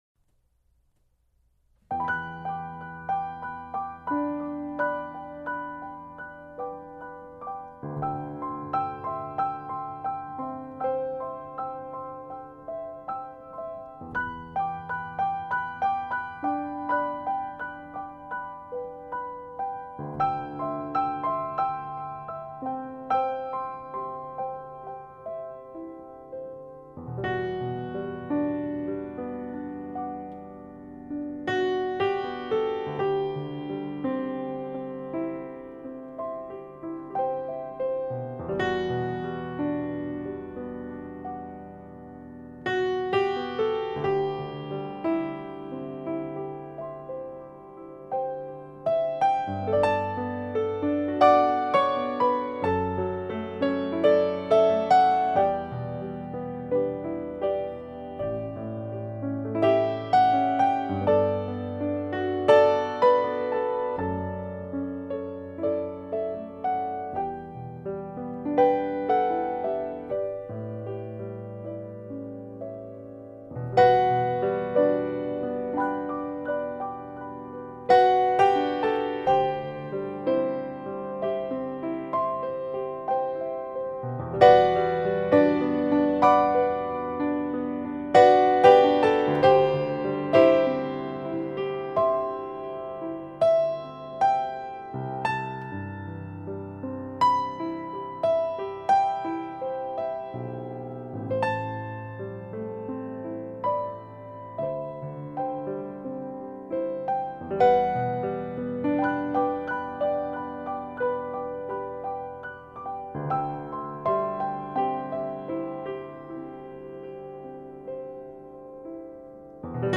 a collection of original piano music with a romantic touch
solo piano